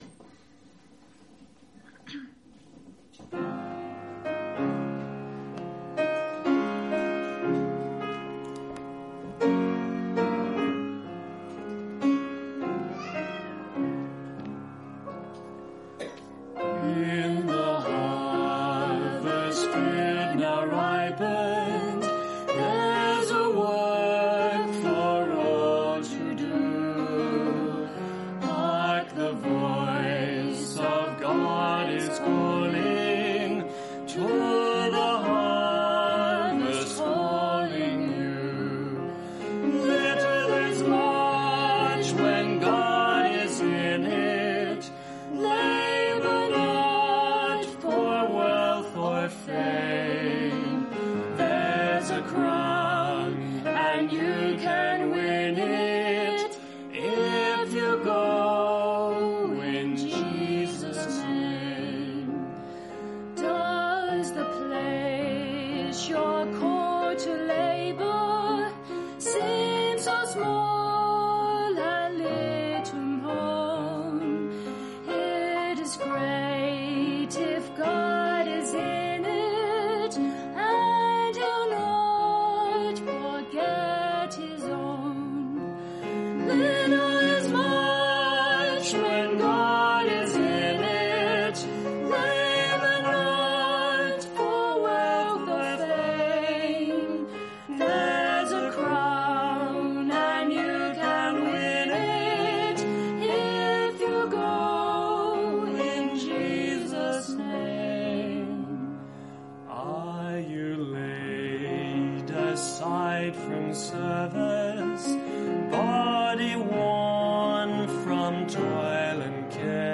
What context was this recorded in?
Series: Lessons On The Holy Spirit Service Type: Sunday Morning